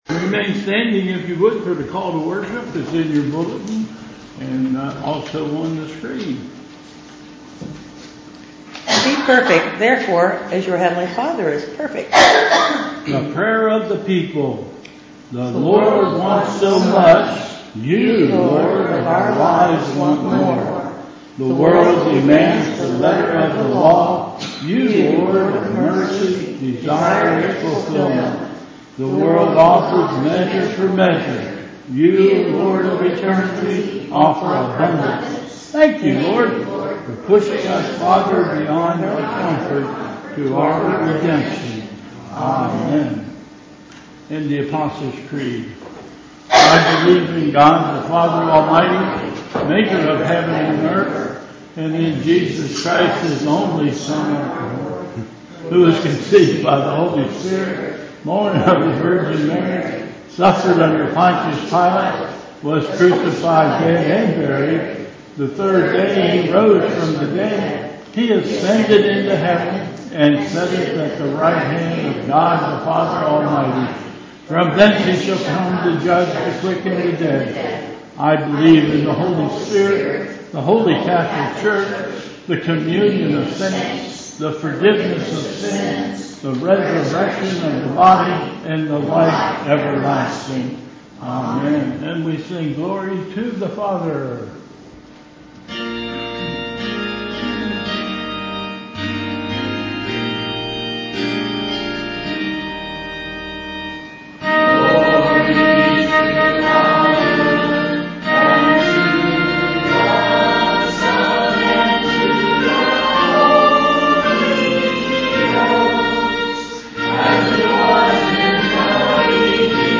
Bethel Church Service
Call to Worship